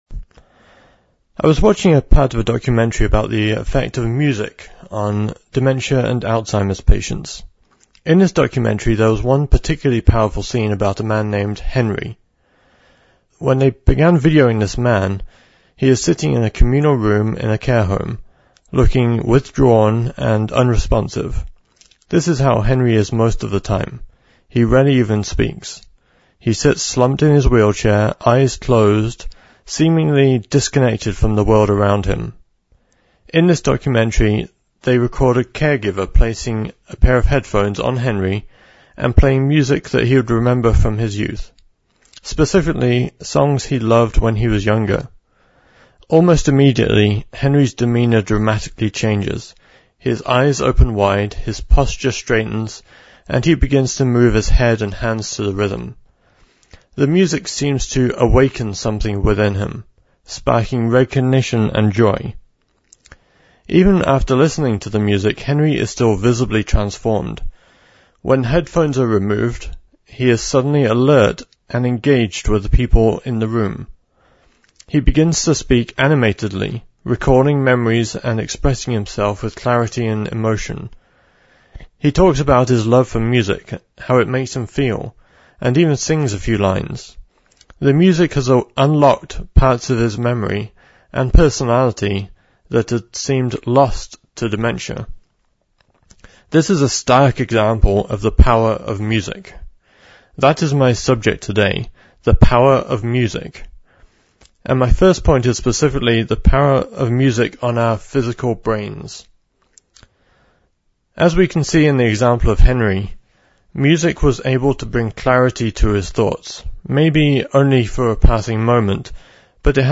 Split Sermon